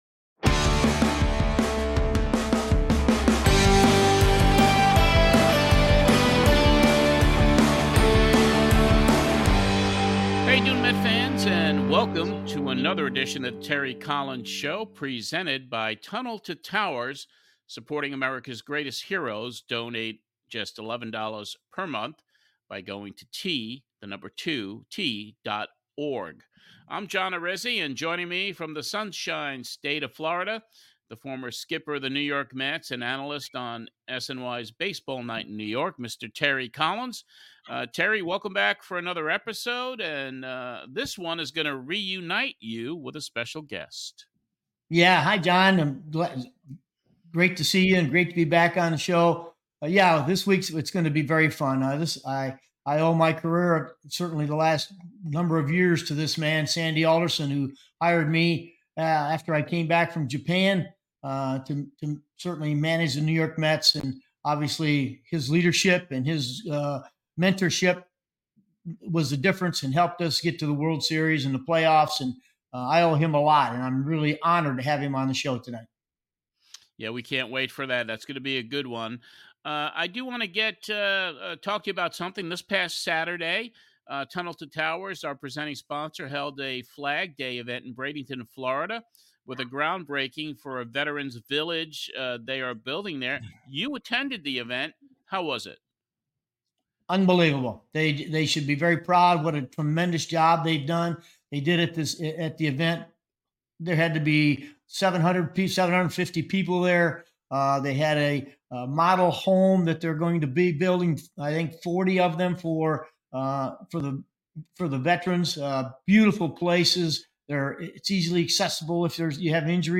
On another very special episode, Terry Collins welcomes the architect of the 2015 National League Champion NY METS. We discuss the one player Sandy was sorry he let go after the 2015 season, shares insides stories of his days leading the Mets front office, including those 5 days in July that turned around the Mets fortunes that year.
Host: Terry Collins